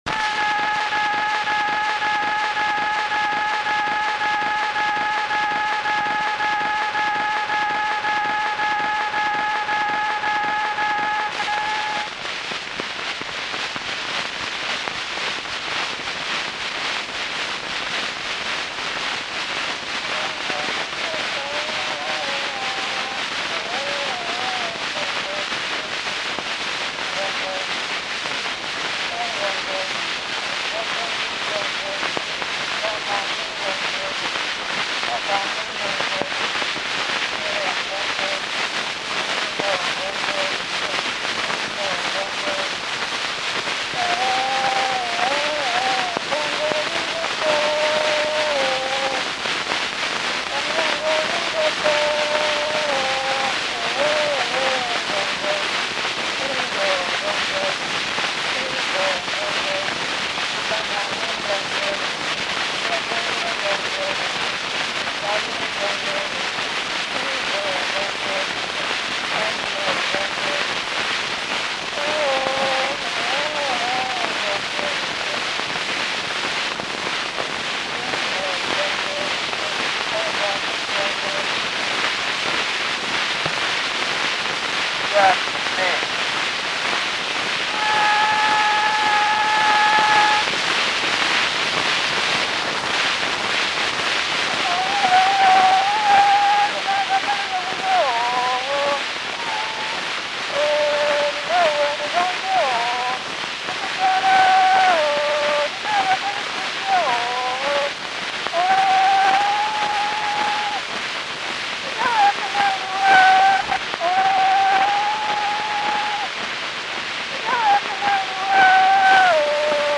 From the sound collections of the Pitt Rivers Museum, University of Oxford, being from a collection of wax cylinder recordings of Zande songs, dances and spoken language made by social anthropologist Edward Evans-Pritchard in South Sudan between 1928 and 1930.
Recorded by Edward Evan Evans-Pritchard.